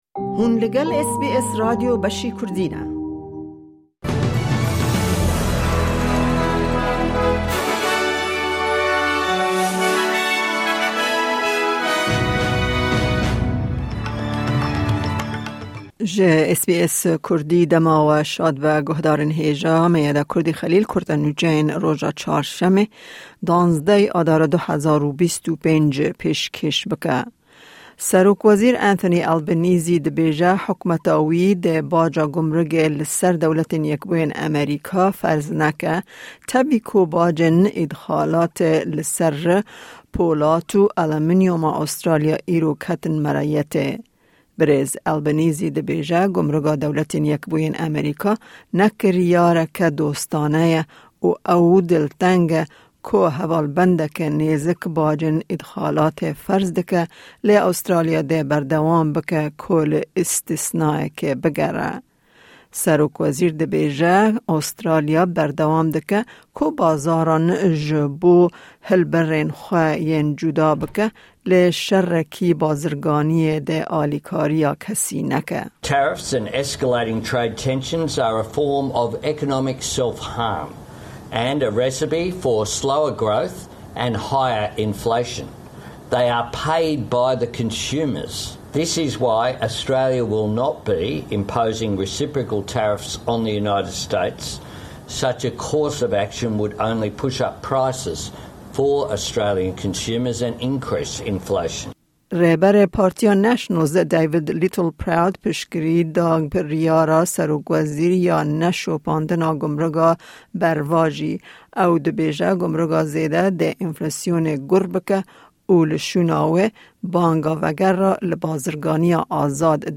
Kurte Nûçeyên roja Çarşemê,12î Adara 2025